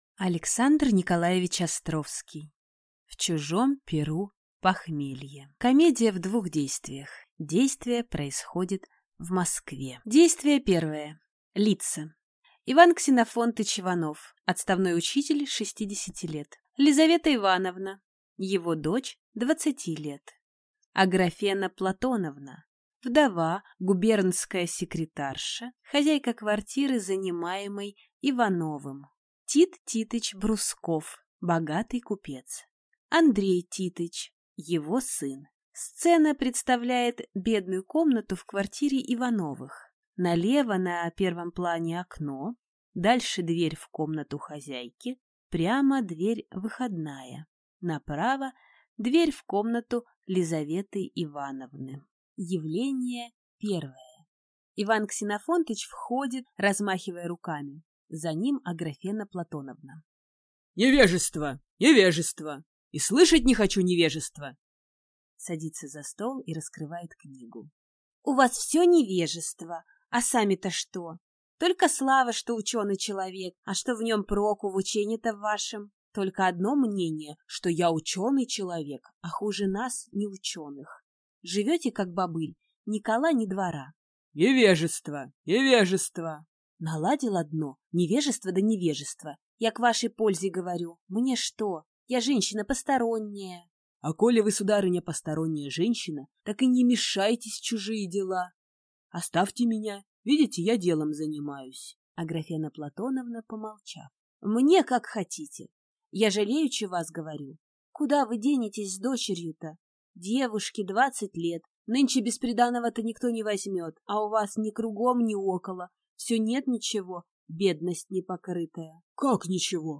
Аудиокнига В чужом пиру похмелье | Библиотека аудиокниг